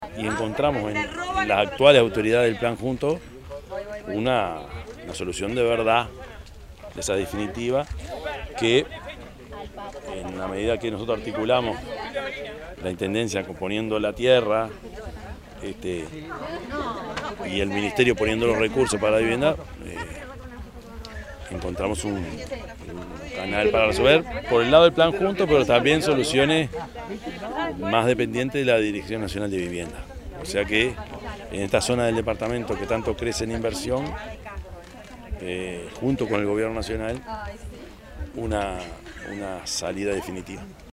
yamandu_orsi_intendente_de_canelones_1.mp3